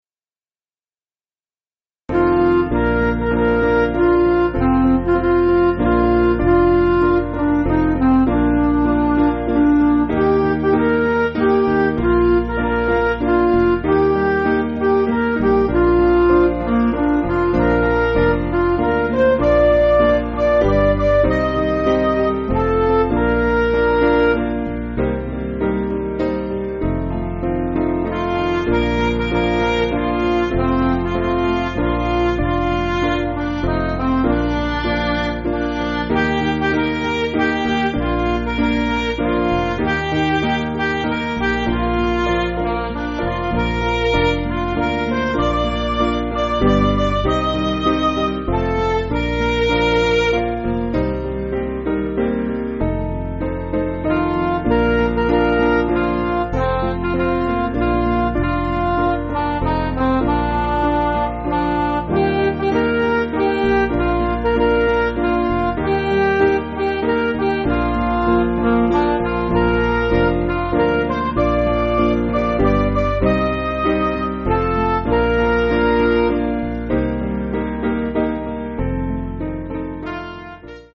Basic Piano & Organ
(CM)   3/Bb 512.7kb